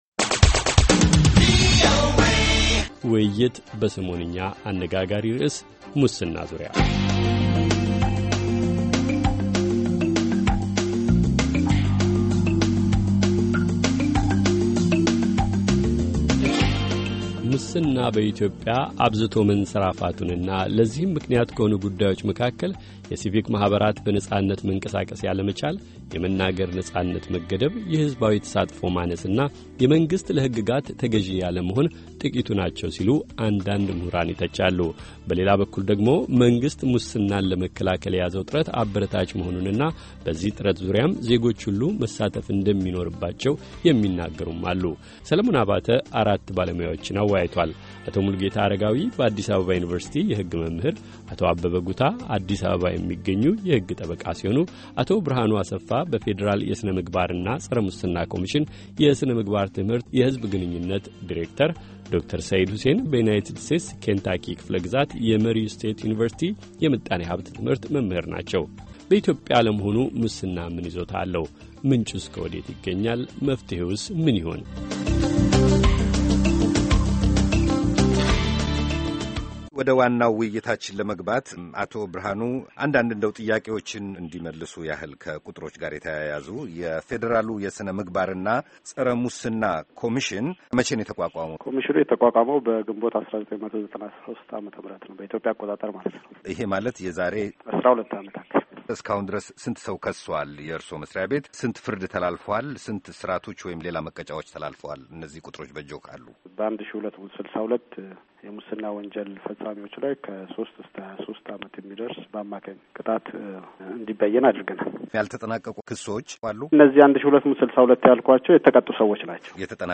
ሙስና በኢትዮጵያ፤ መንስዔና መፍትሔ - ሙሉ ውይይት